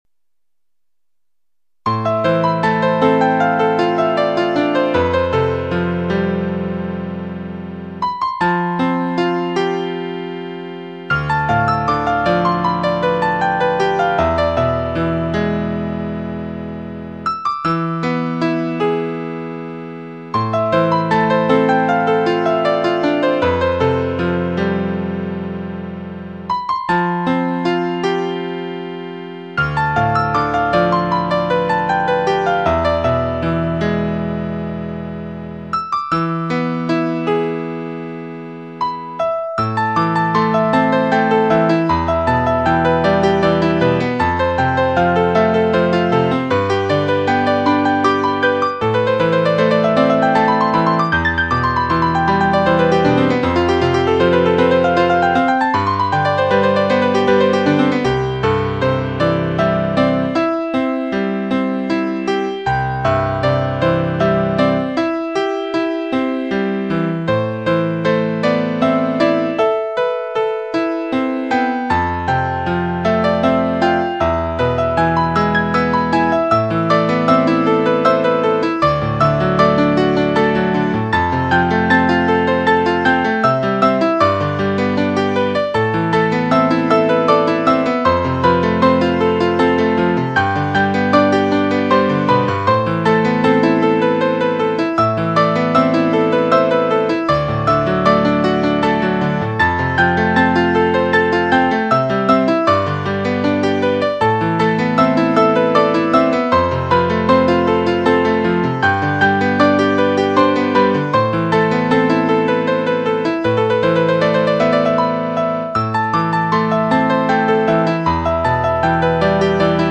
当前位置：首 页 > 清音雅韵 >天籁钢琴 > 查看文章